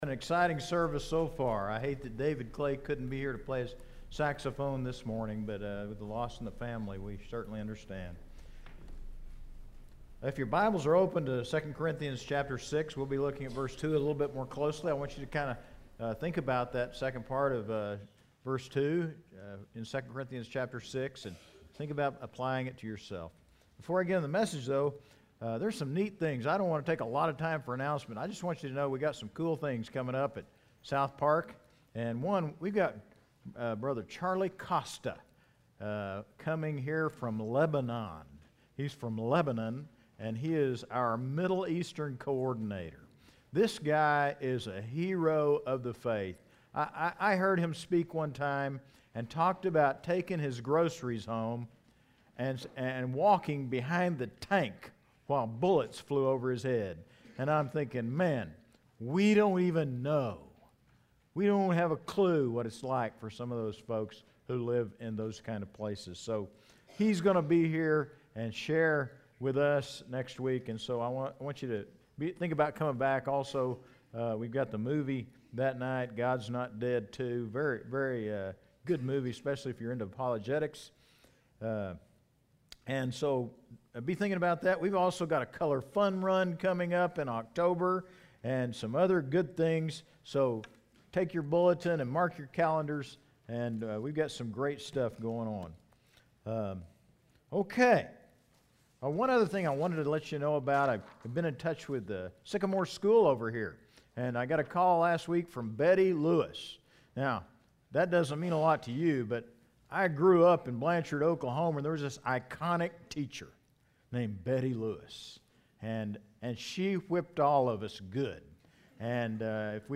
Sermons - South Park Baptist Church